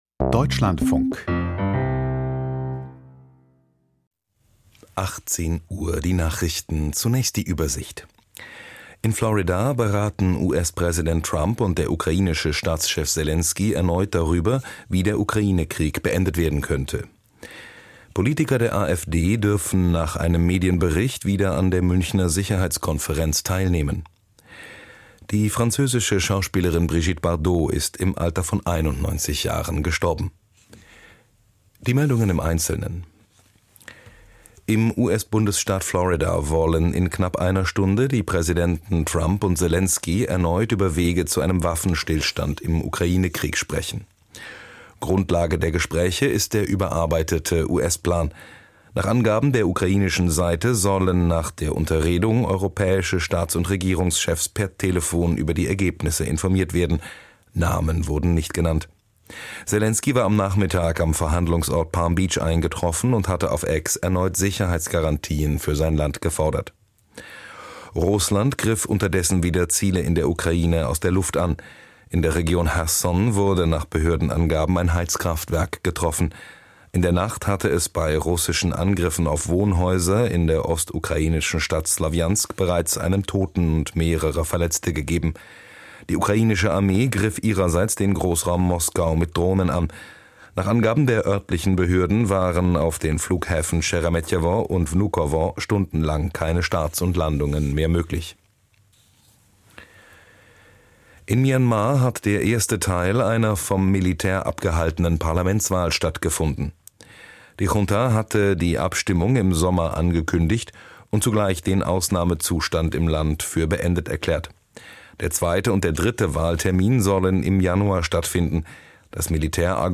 Die Nachrichten vom 28.12.2025, 18:00 Uhr